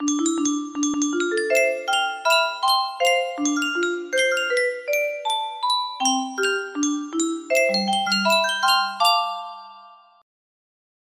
Grand Illusions 30 (F scale)
This feels like a music box staple!